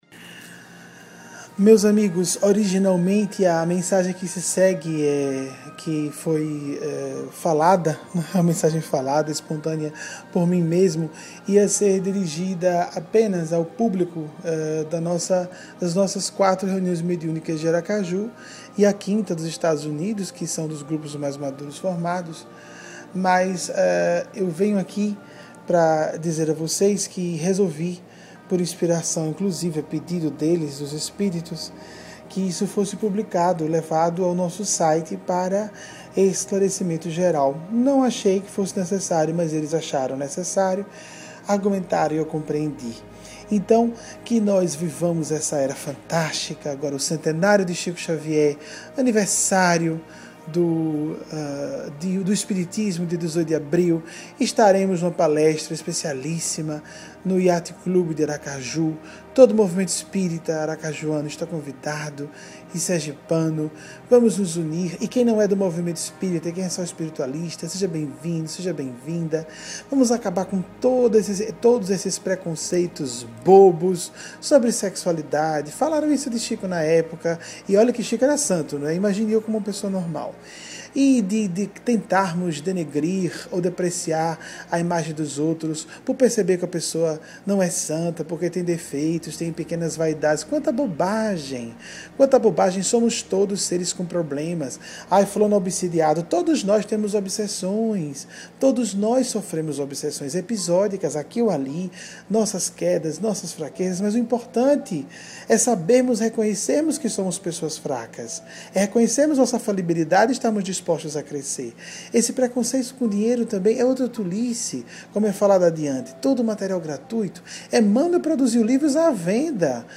A Fala Tocante